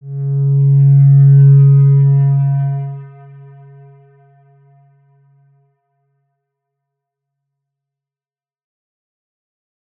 X_Windwistle-C#2-ff.wav